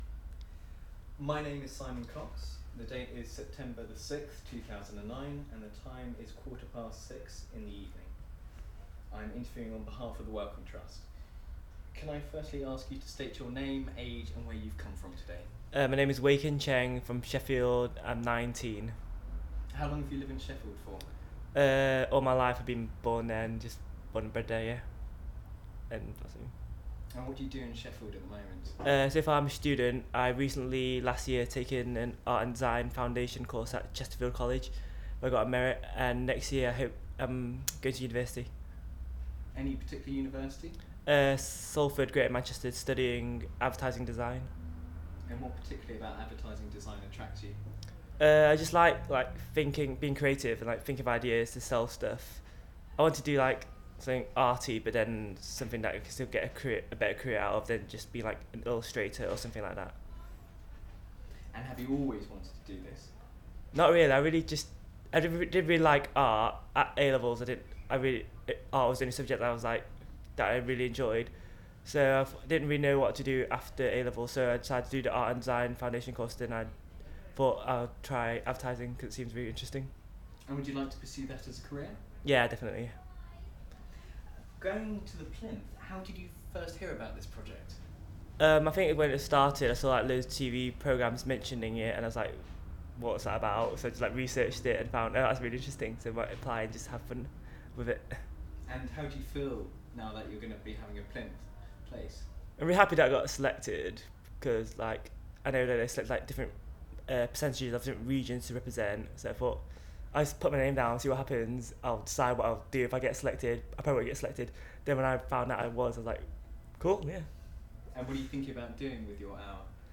Audio file duration: 00:08:15 Format of original recording: wav 44.1 khz 16 bit ZOOM digital recorder.
These recordings are part of the One & Other interview series that has been licensed by the Wellcome Trust for public use under Creative Commons Attribution-non commercial-Share Alike 3.00 UK.